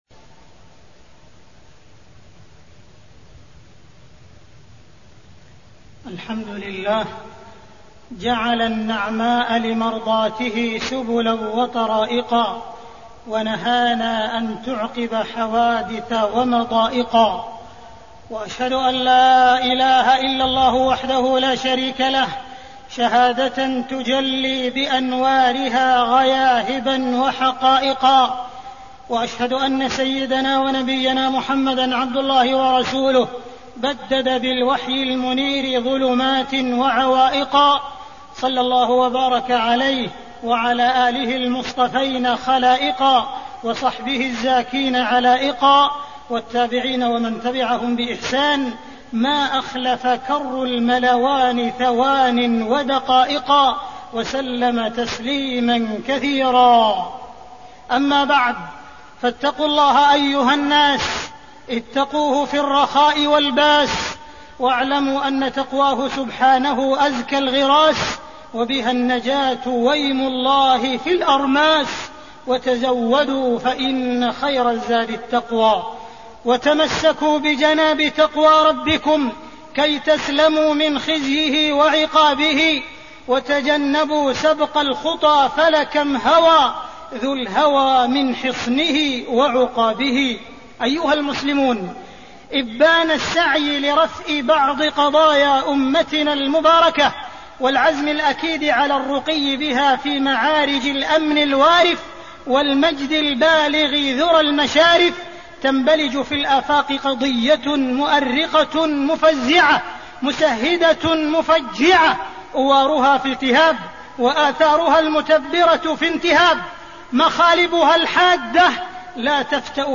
تاريخ النشر ١٧ رجب ١٤٣٠ هـ المكان: المسجد الحرام الشيخ: معالي الشيخ أ.د. عبدالرحمن بن عبدالعزيز السديس معالي الشيخ أ.د. عبدالرحمن بن عبدالعزيز السديس حوادث السيارات وآداب القيادة